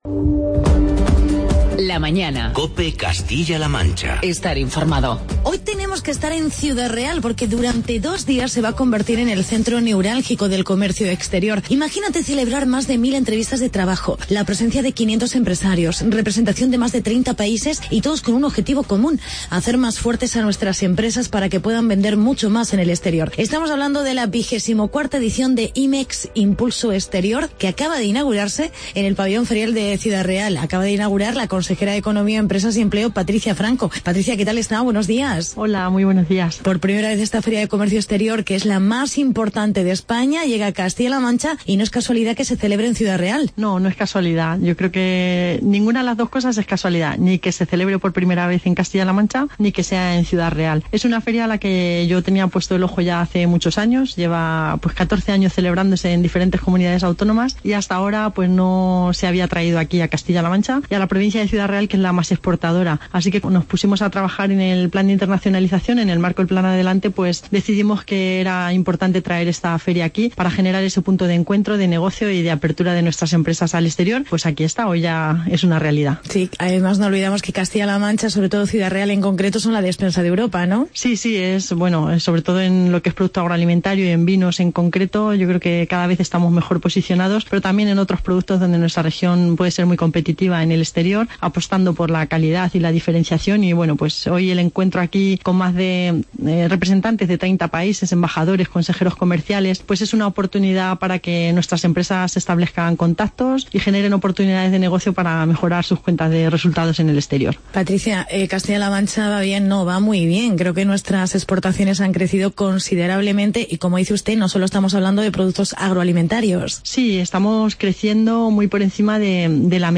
Entrevistamos a la consejera de Economía, Empresas y Empleo, Patricia Franco por la inauguración de IMEX 2016 en Ciudad Real. La mayor feria de comercio exterior de España.